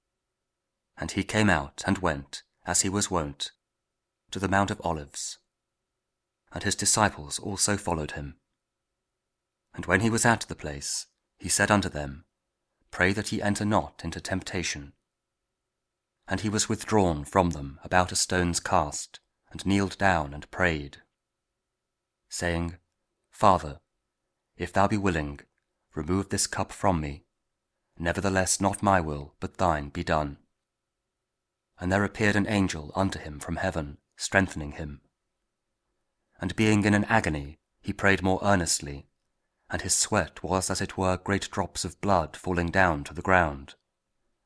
Luke 22: 39-44| King James Audio Bible | KJV | King James Version